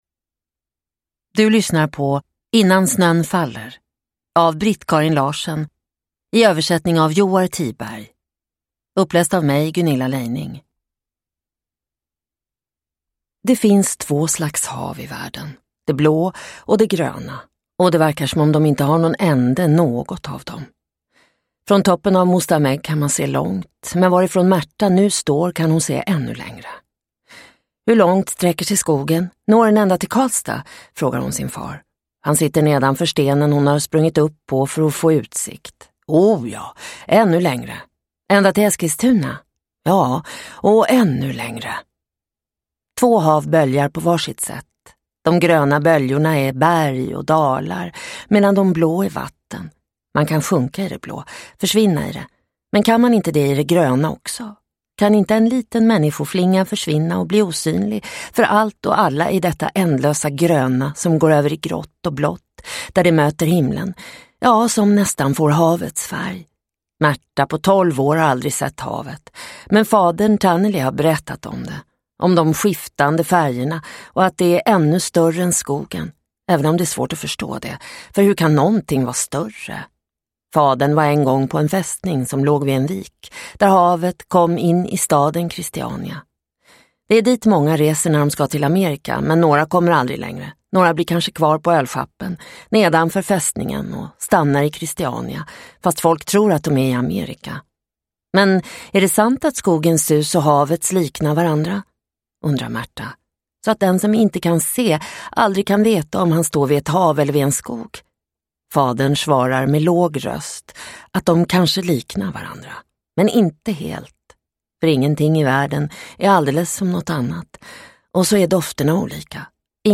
Innan snön faller – Ljudbok – Laddas ner